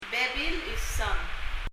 lmuut　　[lmu:t]　　　　　　　　繰り返し　　again
bebil　　　[bɛbil]　　　　　　　　　なにか　　　　some
発音